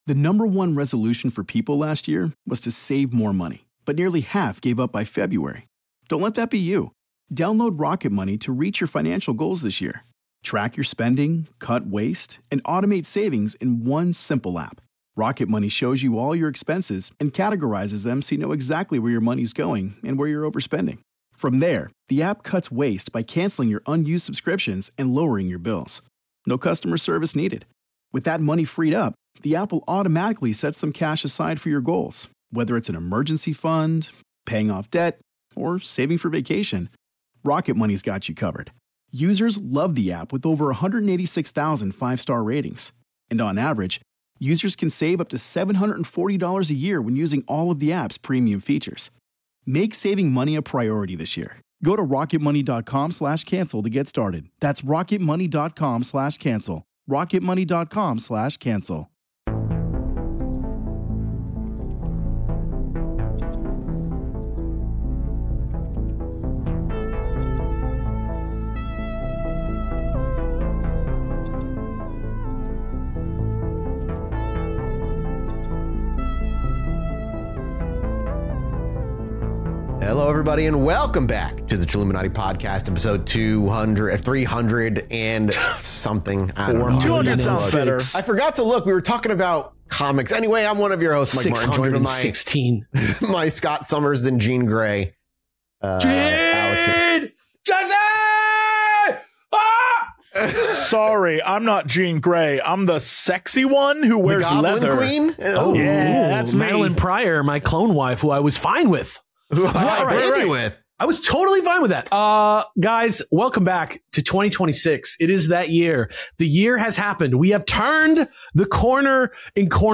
weekly comedy podcast